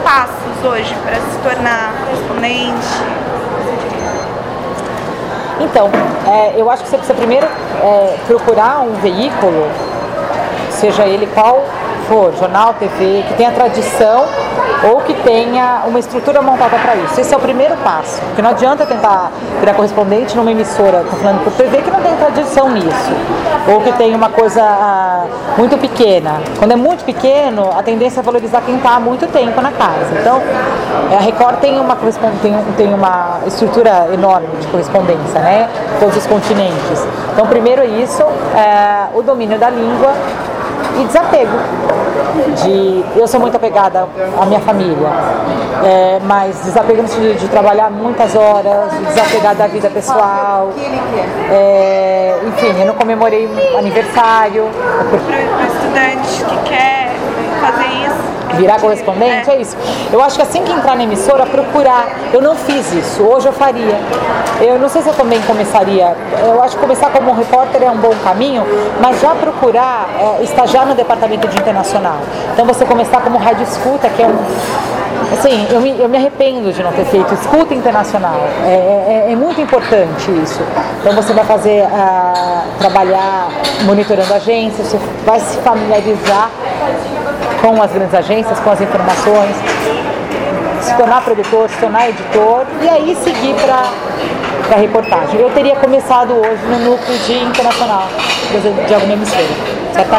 Marcamos a entrevista em um shopping de São Paulo e, munidas de câmeras e gravadores, iniciamos a entrevista.